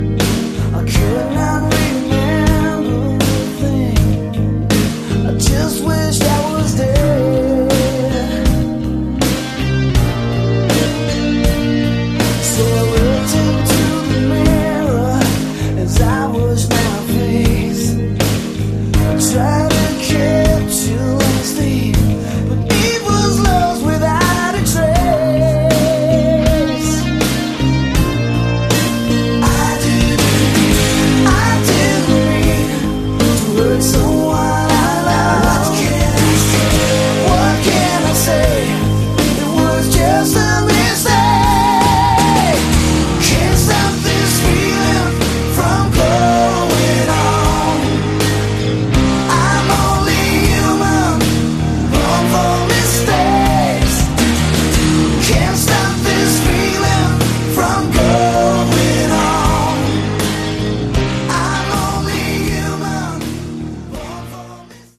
Category: AOR
vocals
guitar
bass
drums
keyboards